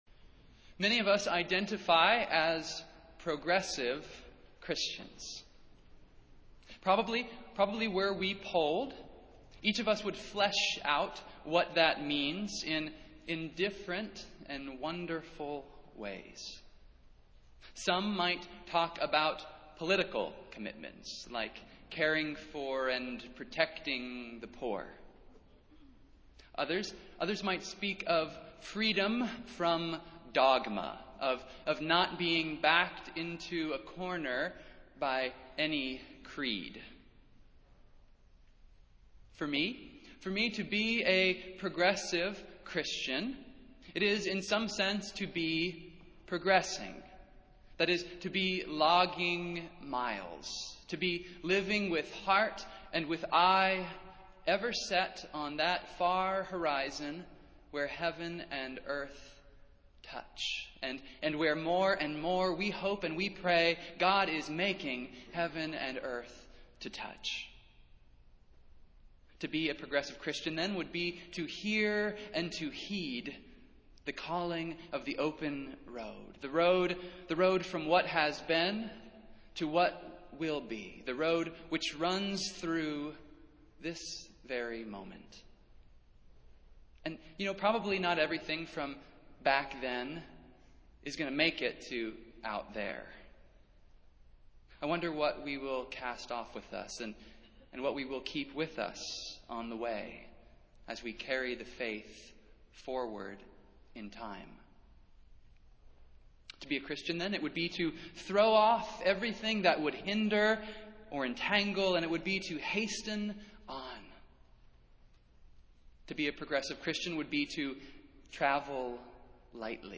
Festival Worship - Twenty-third Sunday after Pentecost